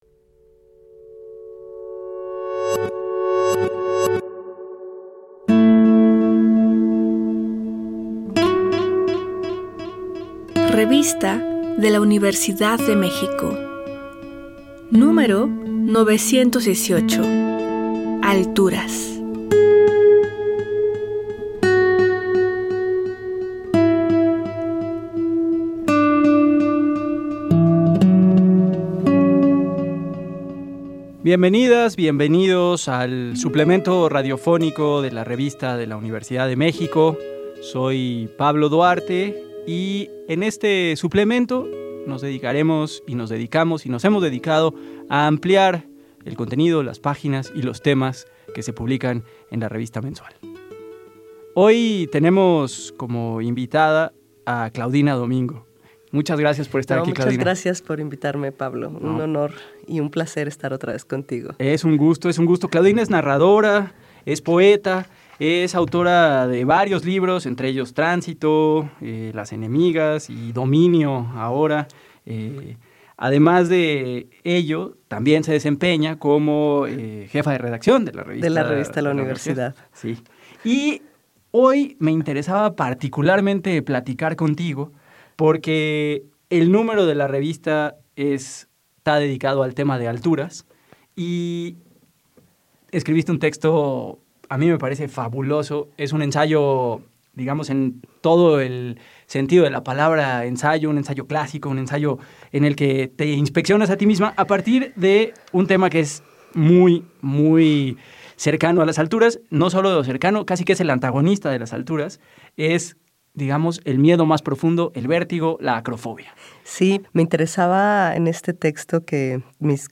Fue transmitido el jueves 27 de marzo de 2025 por el 96.1 FM.